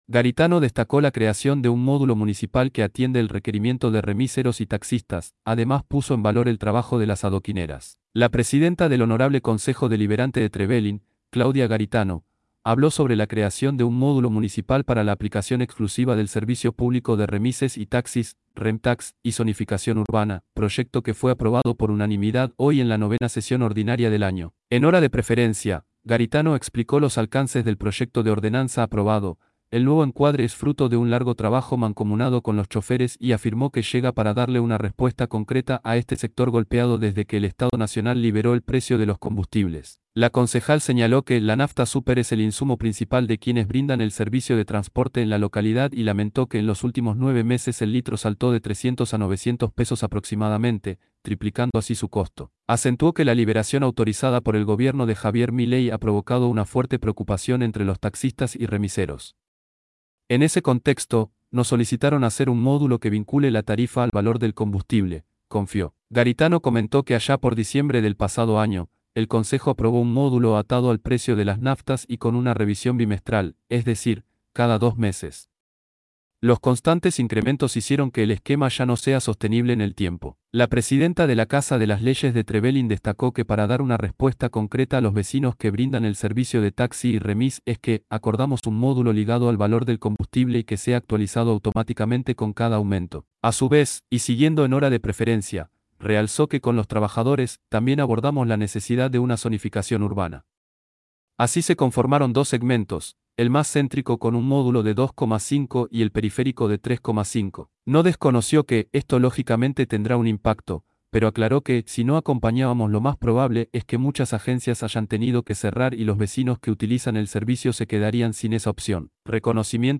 La presidenta del Honorable Concejo Deliberante de Trevelin, Claudia Garitano, habló sobre la creación de un módulo municipal para la aplicación exclusiva del servicio público de remises y taxis “REMTAX” y zonificación urbana, proyecto que fue aprobado por unanimidad hoy en la IX Sesión Ordinaria del año.
claudia_garitano_xi_sesion_hcd_0.mp3